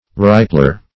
Search Result for " ripler" : The Collaborative International Dictionary of English v.0.48: Ripler \Rip"ler\, Ripper \Rip"per\, n. [Cf. Rip a basket, or Riparian .] (O.E. Law) One who brings fish from the seacoast to markets in inland towns.